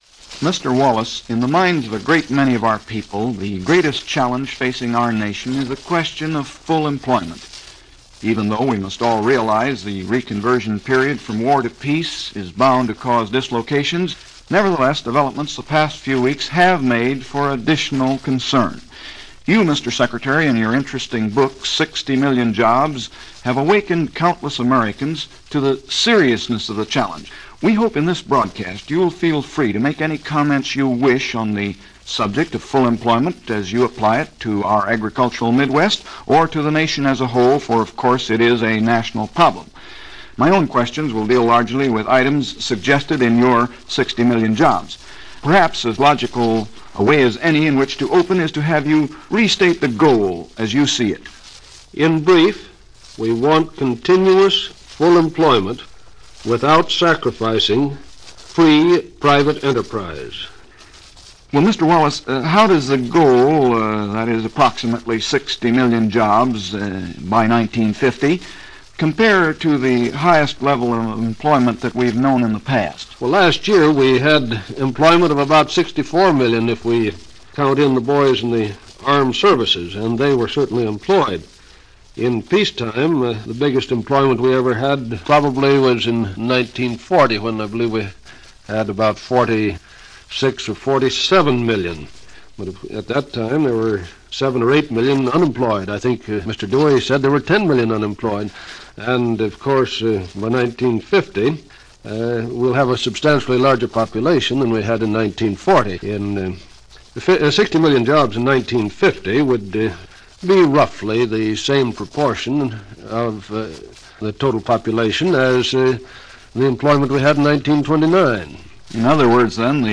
Henry Wallace - Employment In America: "The Goal Is 60 Million" - 1950 - Past Daily Reference Room - Interview from September 26, 1945.